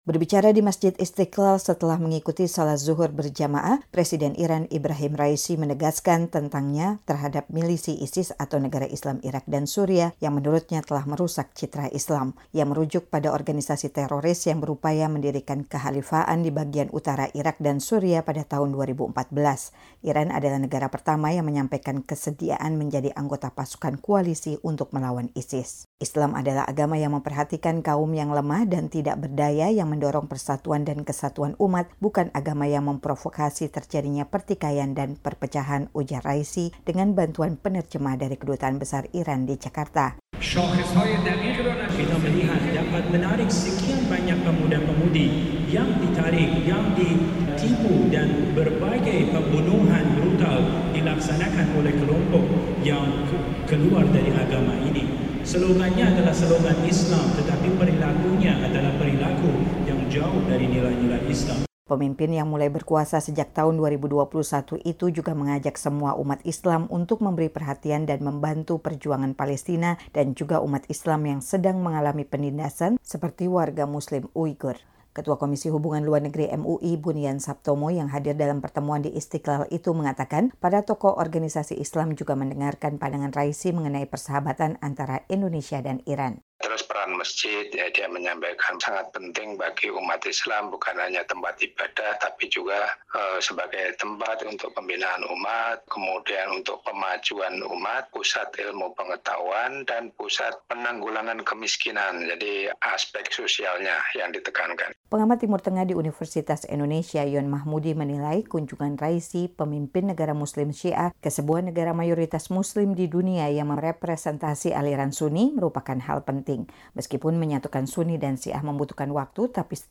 Presiden Iran Ebrahim Raisi berbicara di depan tokoh-tokoh Islam usai salat Dzuhur di Masjid Istiqlal di Jakarta, hari Rabu, 24 Mei 2023.